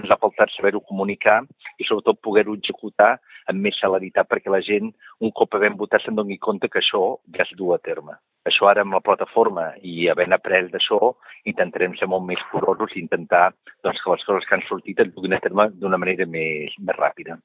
Josep Maria Castells, regidor de l’Àrea de Participació, destaca la bona xifra de participants.
Castells ha destacat a Ràdio Capital que la ciutadania vol veure canvis ràpidament.